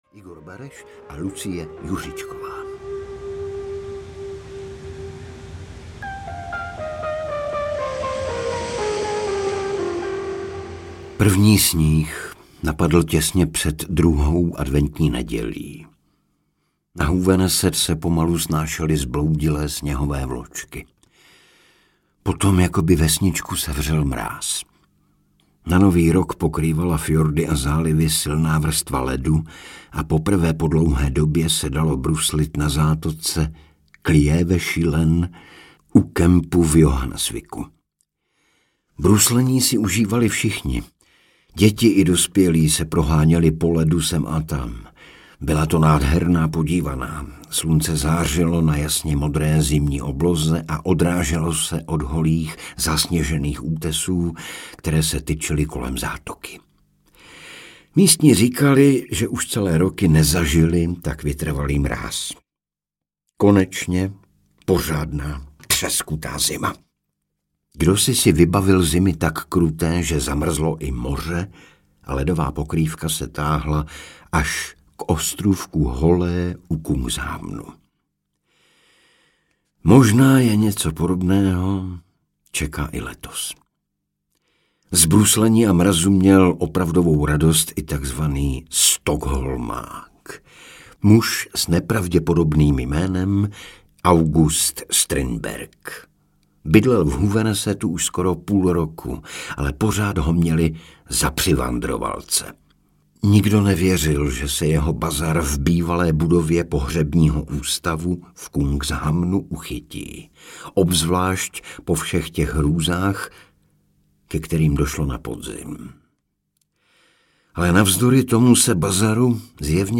Ledoborec audiokniha
Ukázka z knihy
• InterpretIgor Bareš, Lucie Juřičková